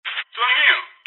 radio_roger.mp3